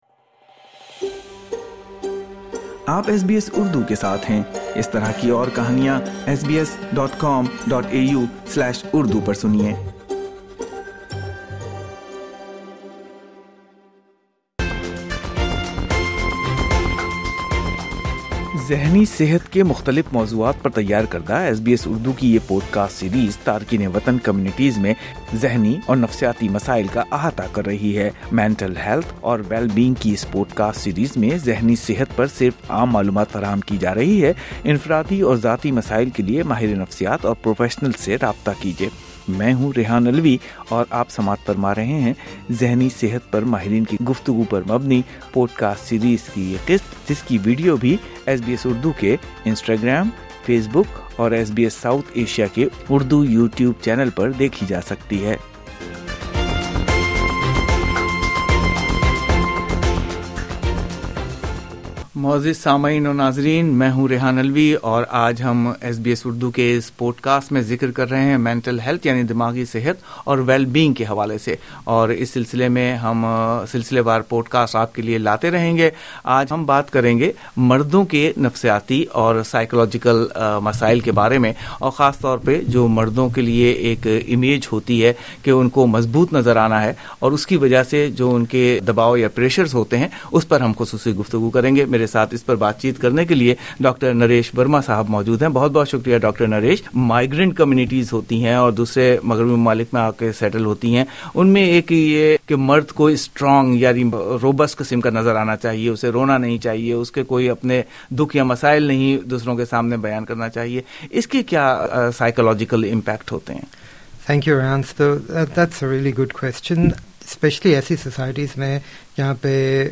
یہ بات چیت صحت مند آسٹریلیا کی ذہنی صحت اور دیکھ بھال پر پوڈ کاسٹ سیریز کا حصہ ہے جس کی ویڈہو یو ٹیوب پر دیکھی جا سکتی ہے ۔